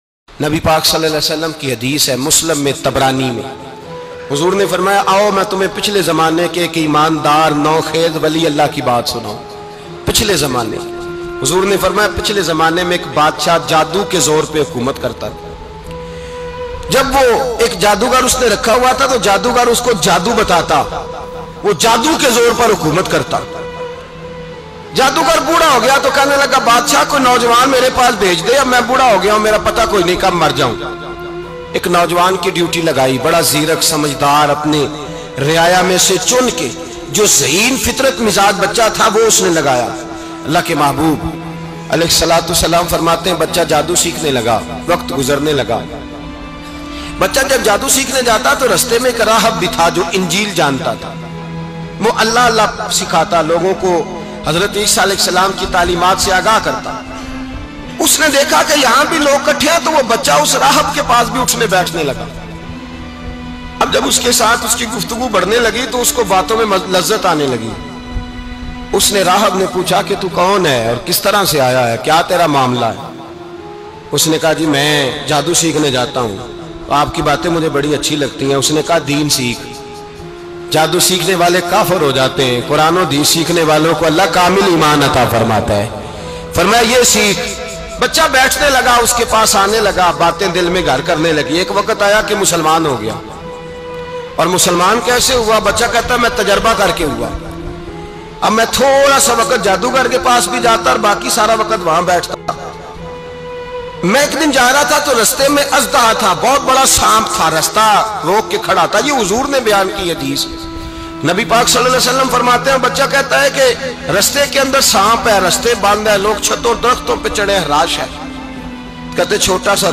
Jadugar Bacha Aur Us Ka Iman Afroz Waqia Bayan MP3 Download